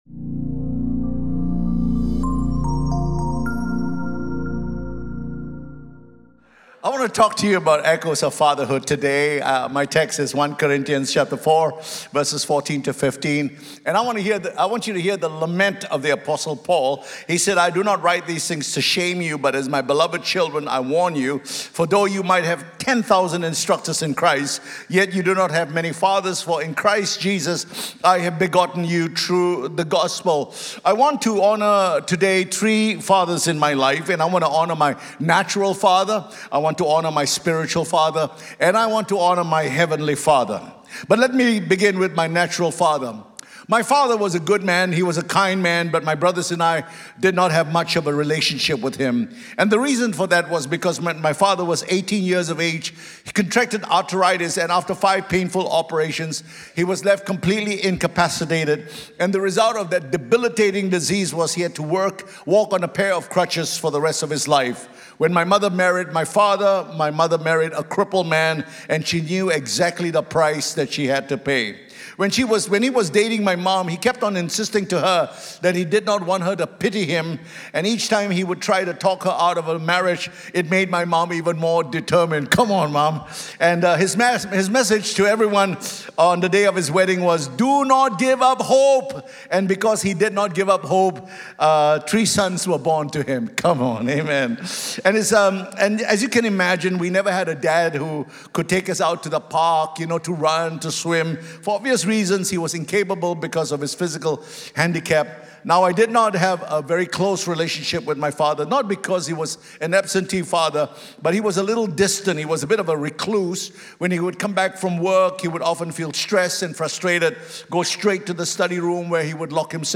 Weekly audio sermons from Cornerstone Community Church in Singapore